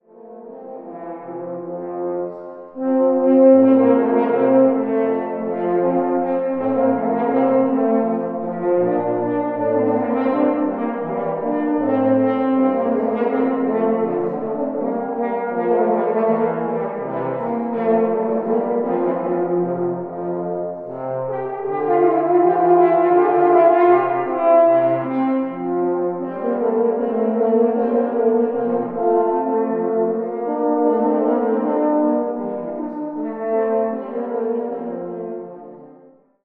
12 Stücke für 2 Hörner
Besetzung: 2 Hörner
12 pieces for 2 French horns
Instrumentation: 2 horns